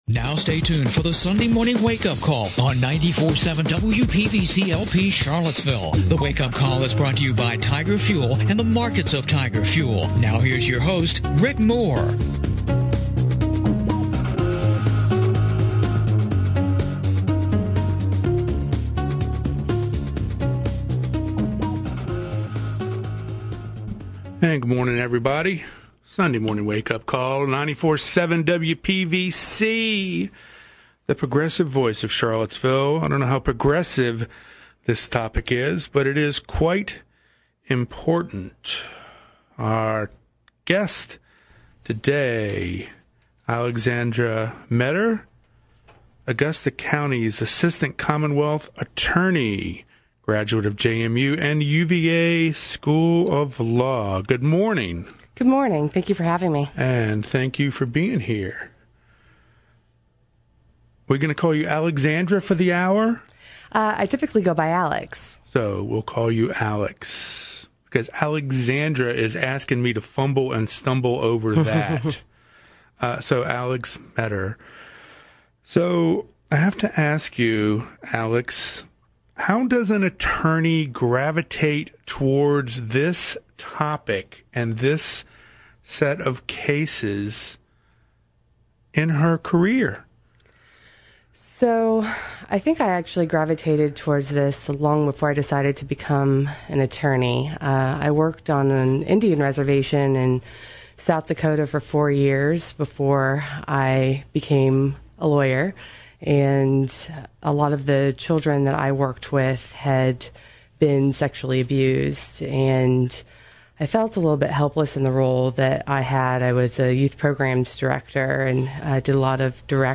talks with Augusta County Commonwealth Attorney Alexandra Meador about her work in prosecuting child sexual abuse cases. Topics include: Reducing the trauma experienced by child victims during the legal process and catching online sexual predators.